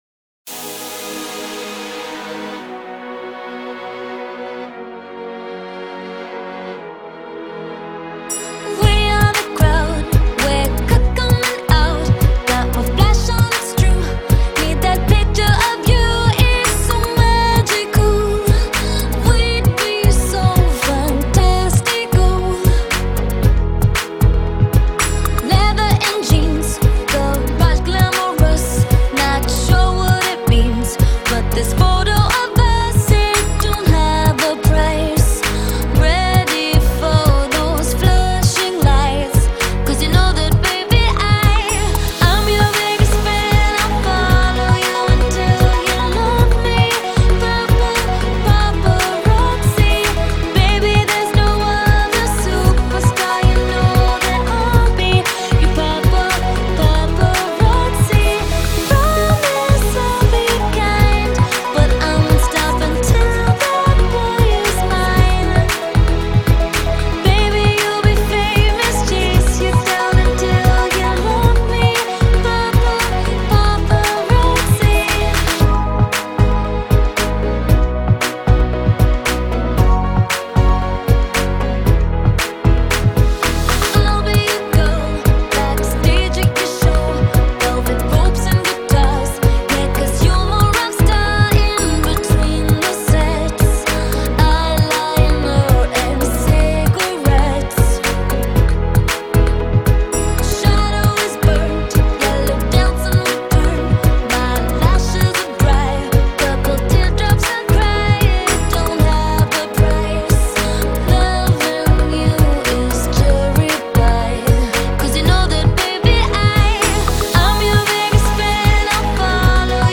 Pop Orchestral Remix